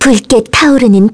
Lewsia_A-Vox_Skill3-1_kr.wav